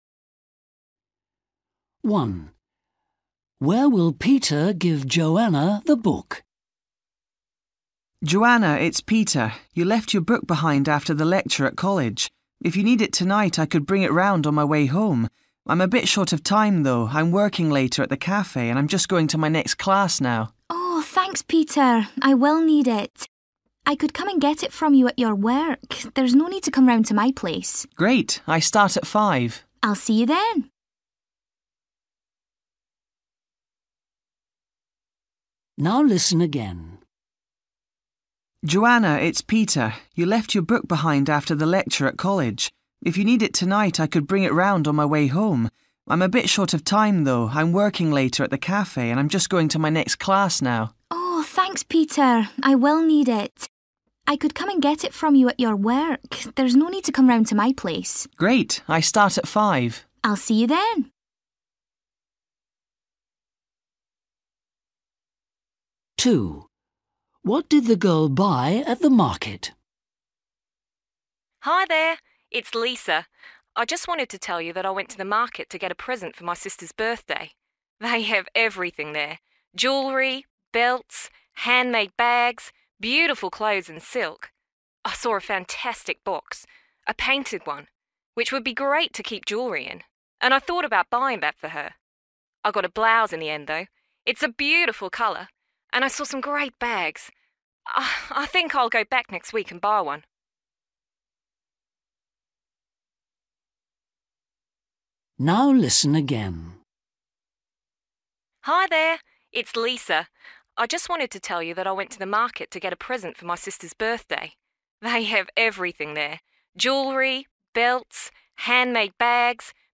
Listening: Everyday Conversations and Activities